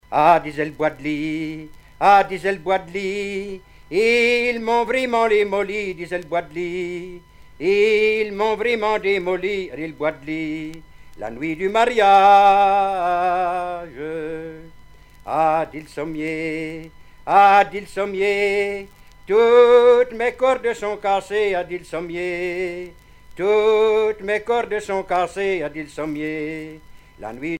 circonstance : fiançaille, noce
Genre énumérative
Pièce musicale éditée